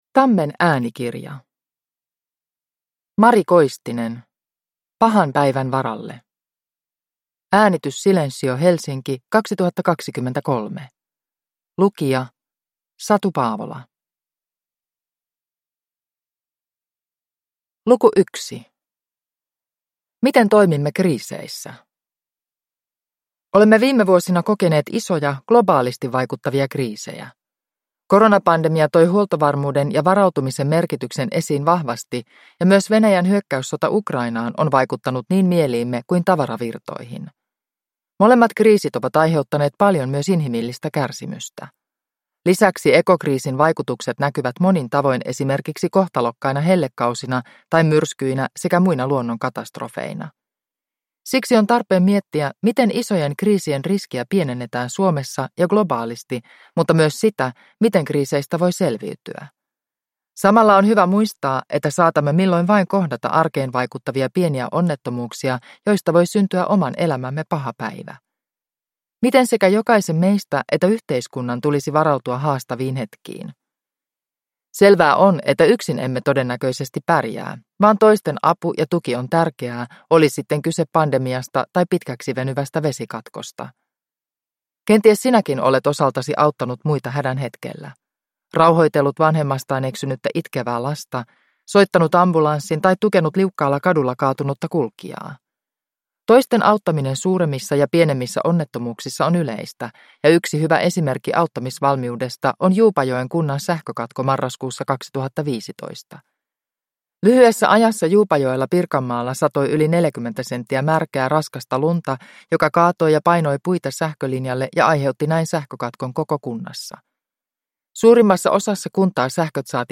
Pahan päivän varalle (ljudbok) av Mari Koistinen